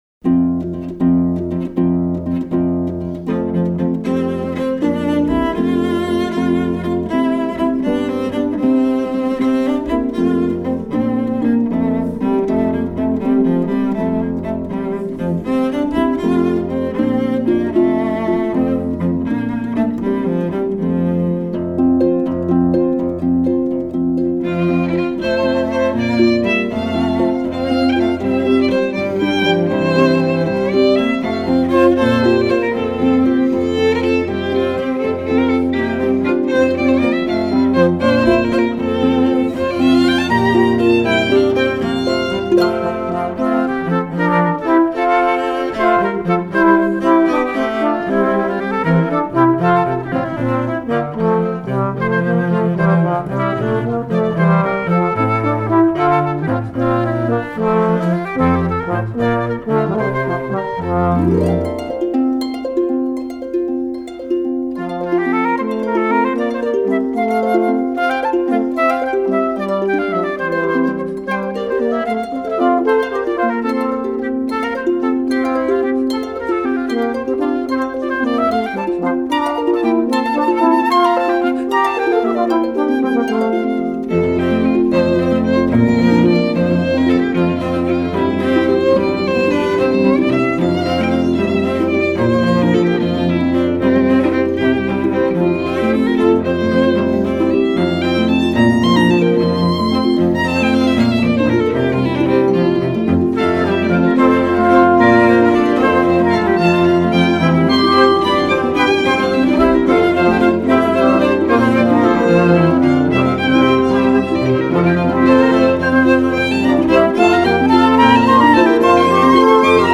traditional Besançon carol